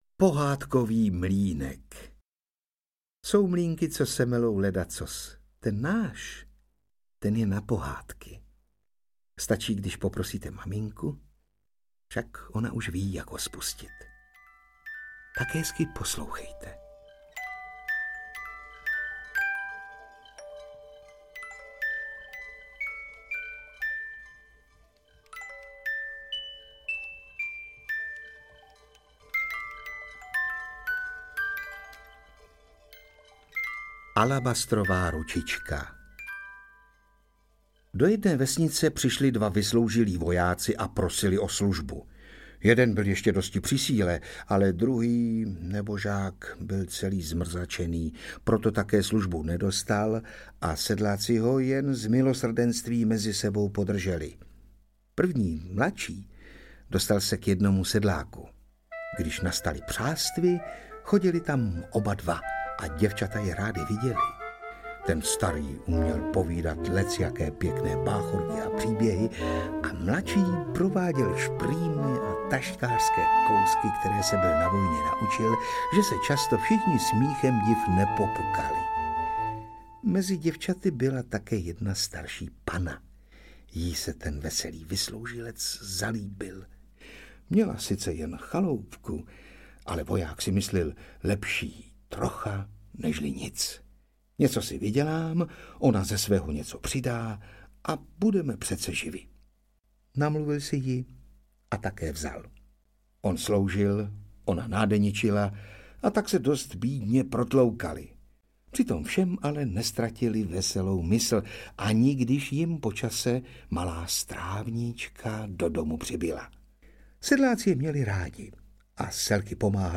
Audiokniha
Čte: Václav Postránecký